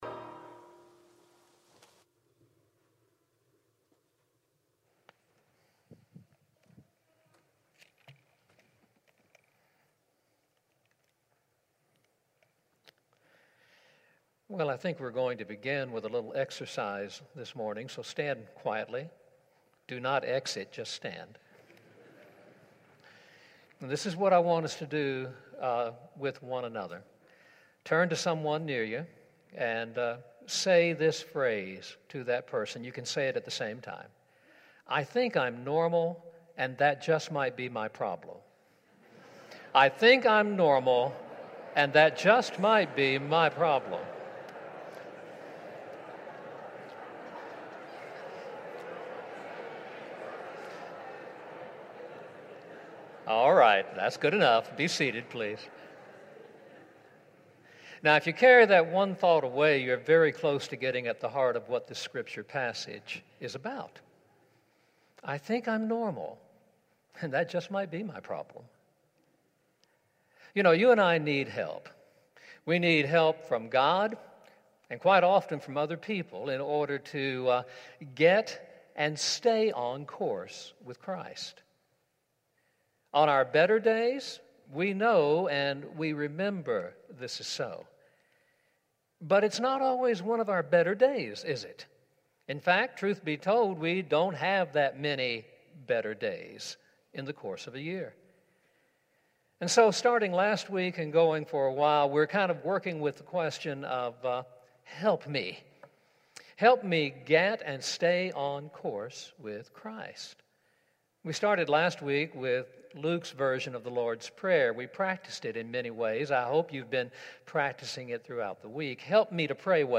A message from the series "Help Me (Classic)."